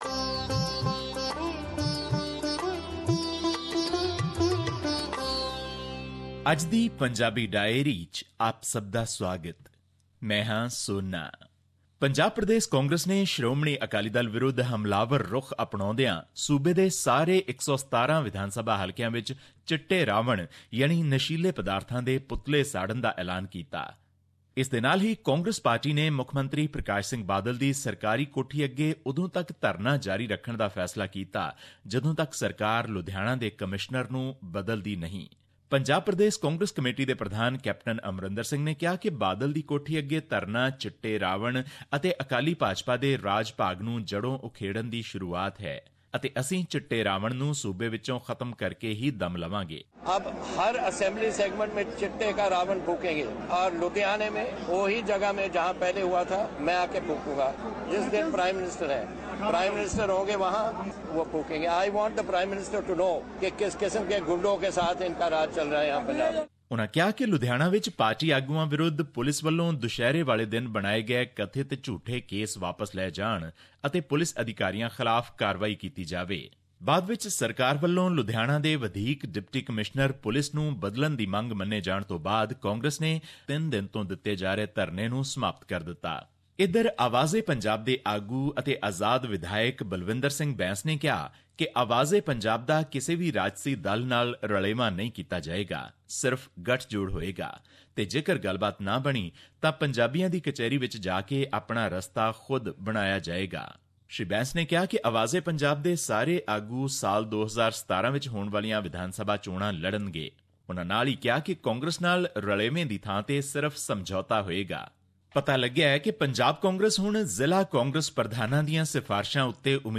Here is your weekly dose of news from Punjab